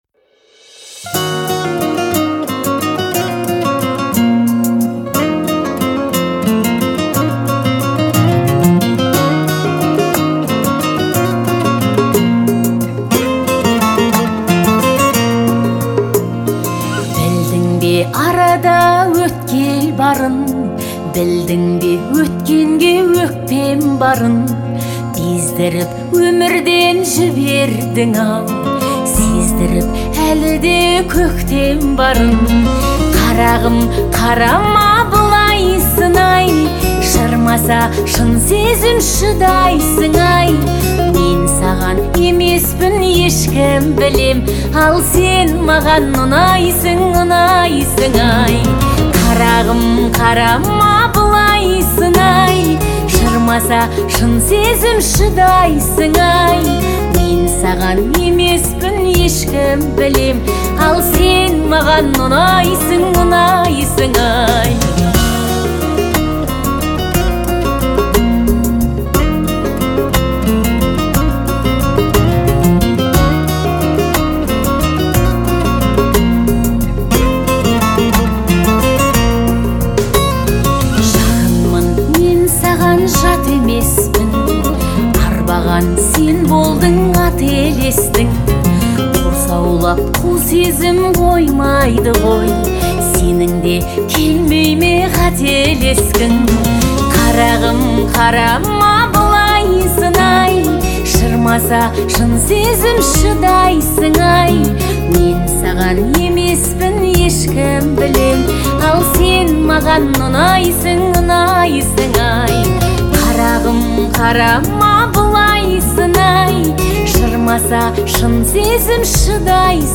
нежный вокал